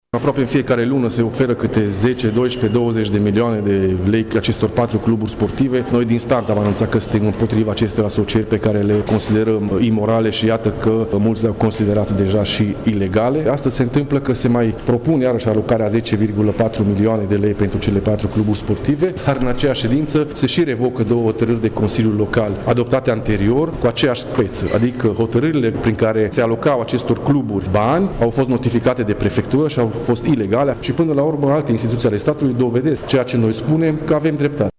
Este vorba de punctele 28 și 29 din octombrie 2016 care au fost revocate, și care implicau sume de bani pentru aceleași cluburi sportive, a precizat Radu Bălaș: